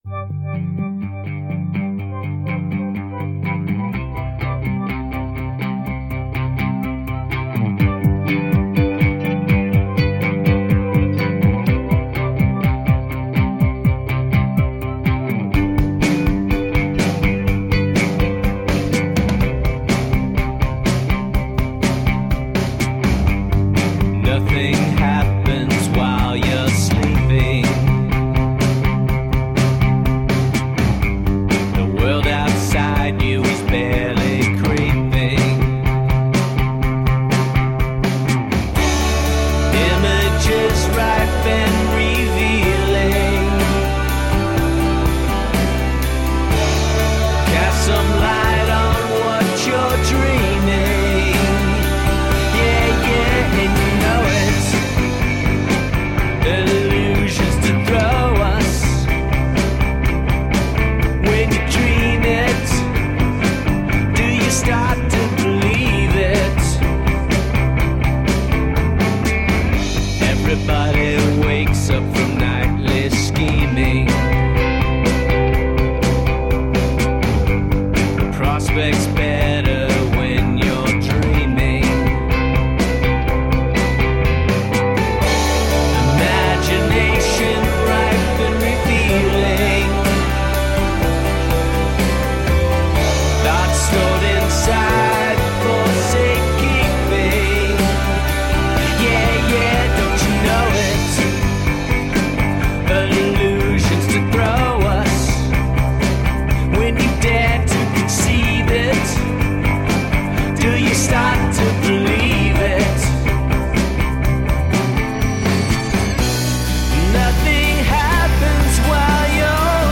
Amplified introspection powered by a single voice.
Tagged as: Alt Rock, Folk-Rock, Pop